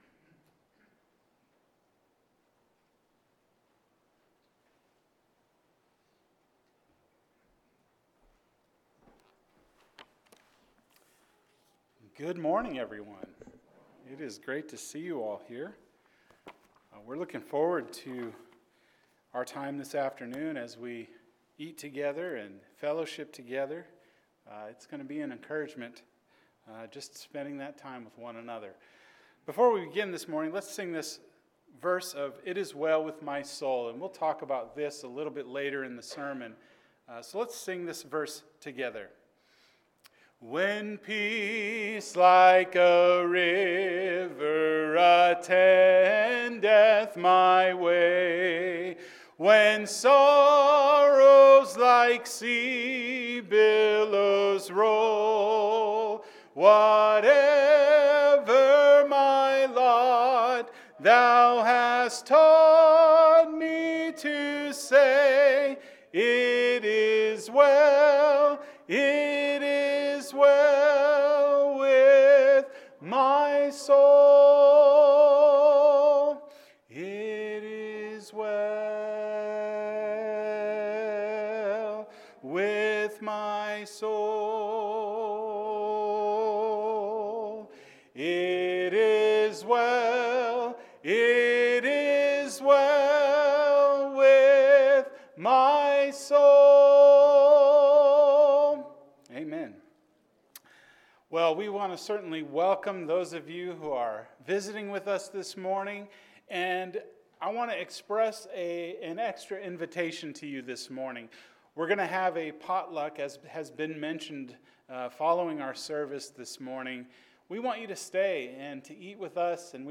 The Beatitudes – The Meek – Sermon